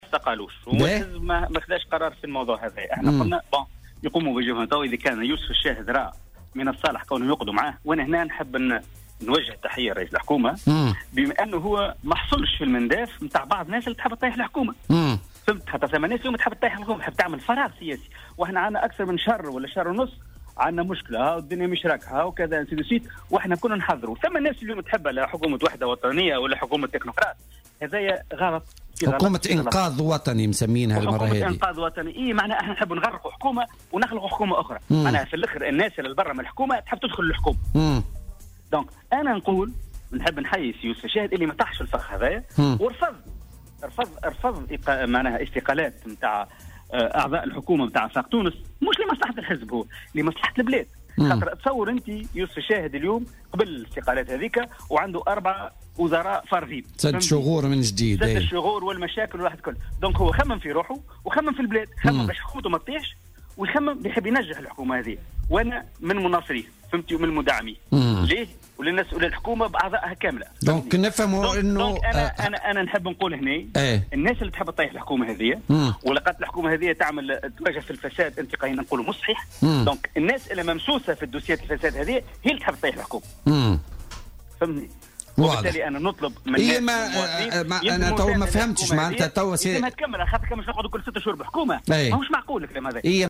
وأضاف في مداخلة له اليوم في برنامج "بوليتيكا" أن الشاهد رفض استقالات أعضاء الحكومة عن حزب آفاق تونس مراعاة لمصلحة البلاد، مشيرا إلى أن بعض الأطراف كانت تنتظر الظفر بهذه المناصب بعد قبول الاستقالة.